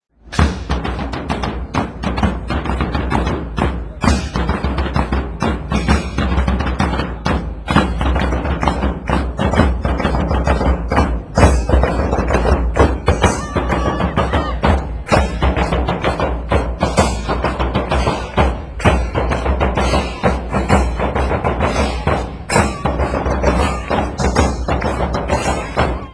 oposito_tambours.wma